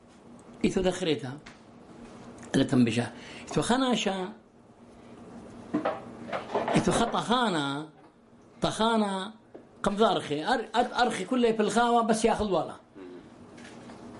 Barwar: The Fox and the Miller